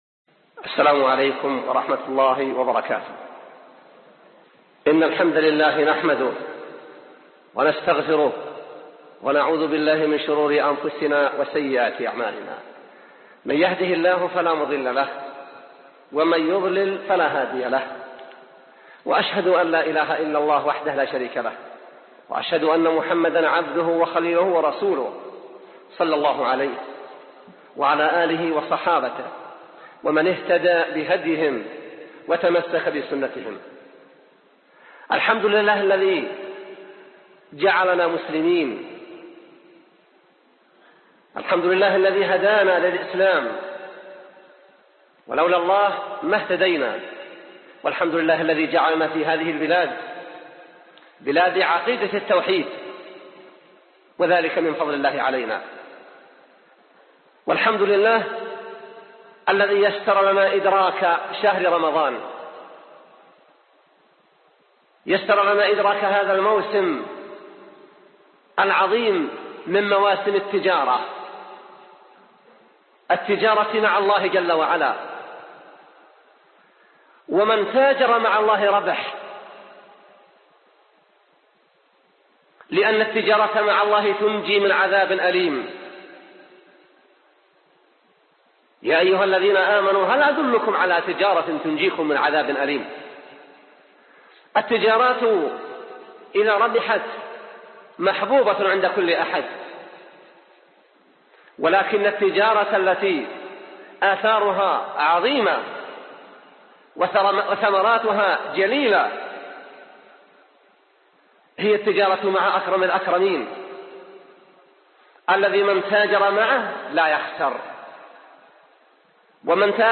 محاضرة - تهيئة النفس لاستقبال شهر رمضان المبارك